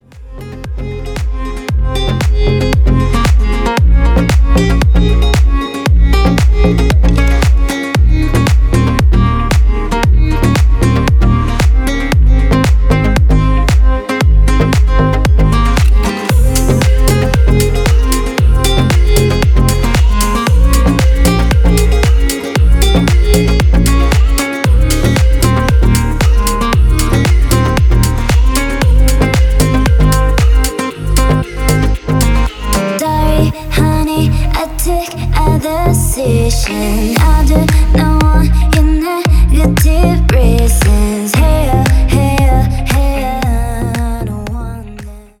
• Качество: 320, Stereo
гитара
атмосферные
Electronic
спокойные
красивая мелодия
Electronica
чувственные
красивый женский голос
Стиль: deep house.